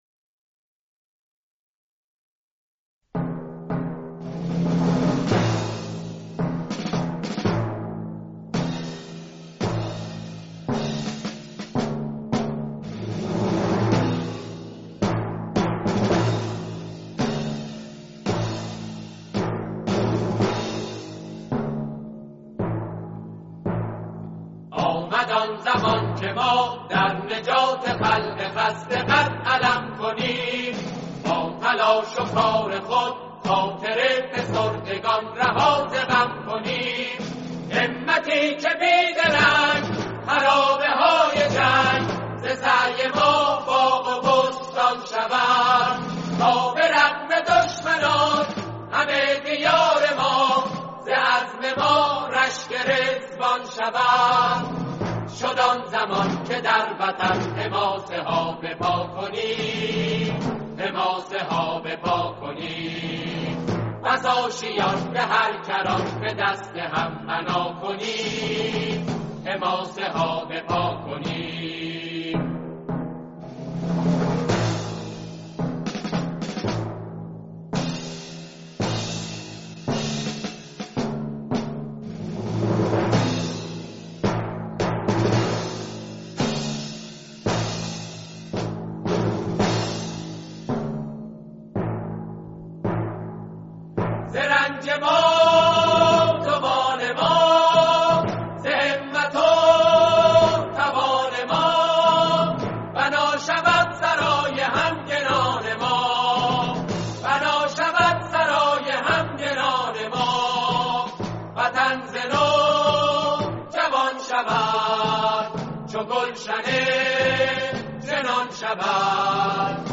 سرودی